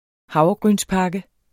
Udtale [ ˈhɑwʁʌgʁyns- ]